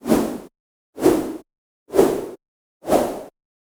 yell-sounds-swish-64bpm-1beat.ogg